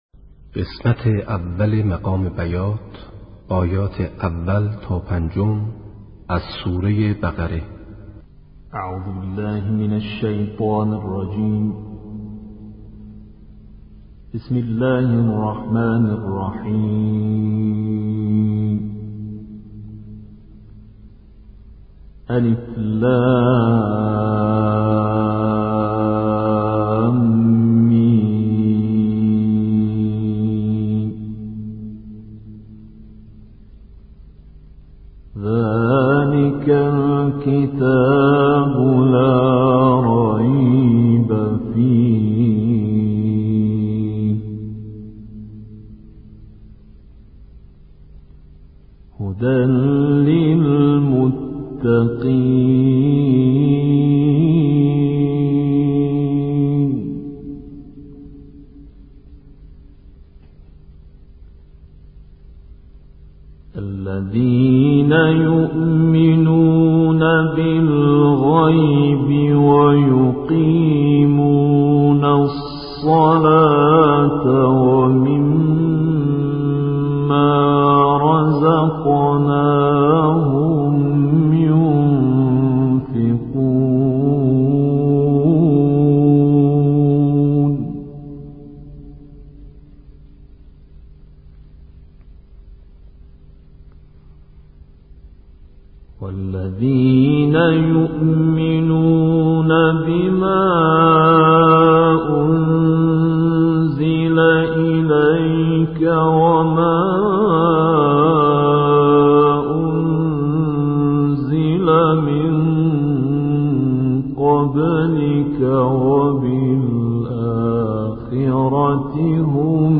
قرار مقام (نغمه) بیات
نغمه قرائت
قرار-بیات.mp3